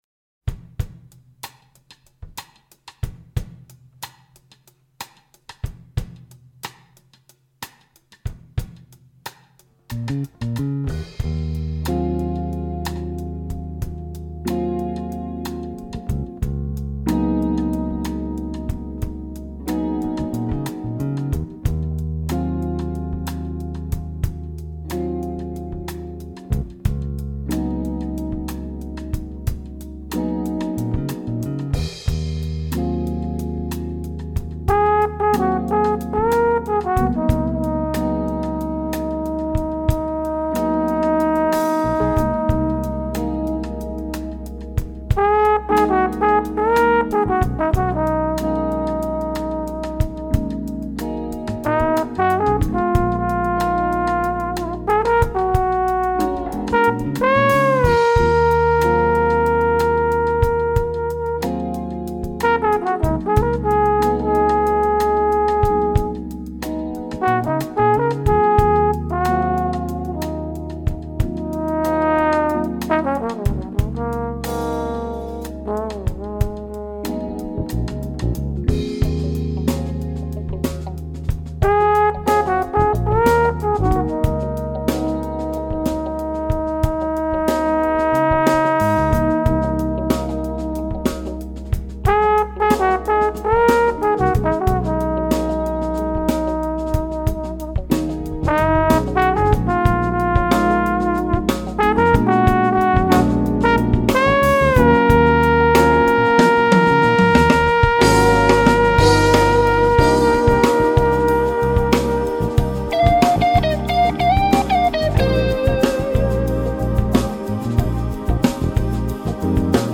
drums
trombone
guitar
bass